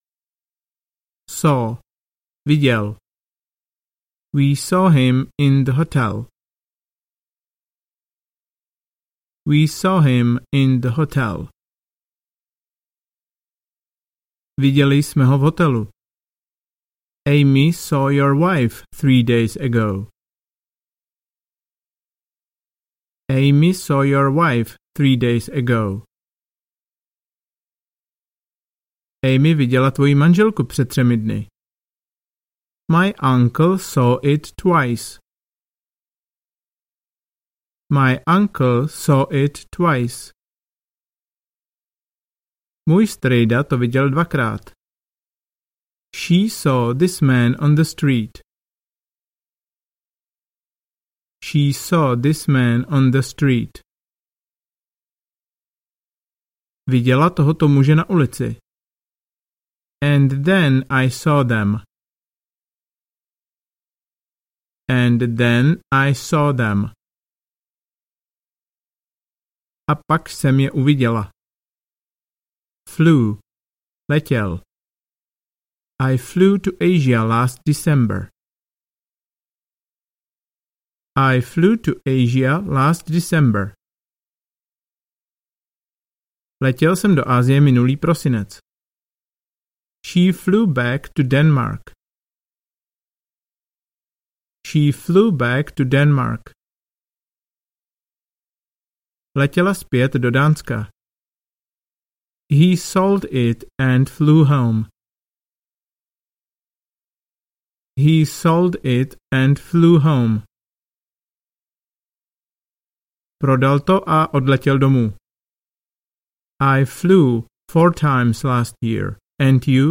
Větičky na nepravidelná slovesa audiokniha
Ukázka z knihy